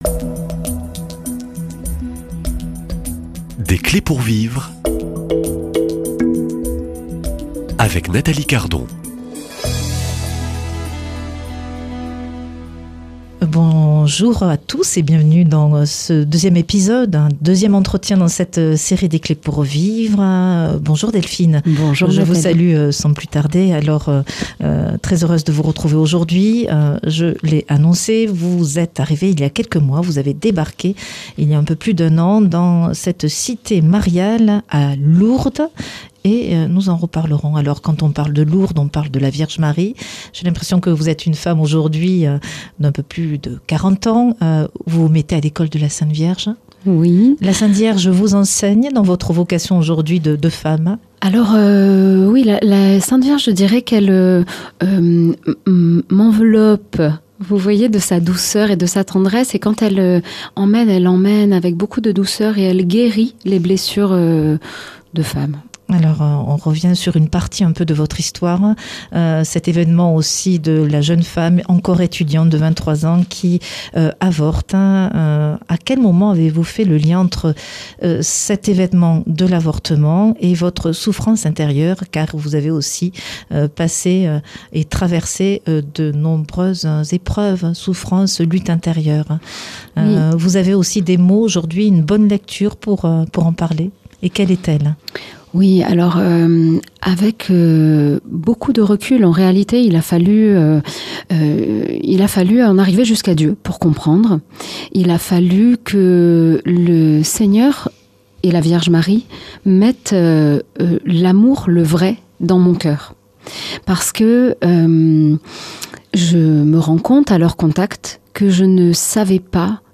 Un témoignage poignant de transformation, de guérison et de résilience.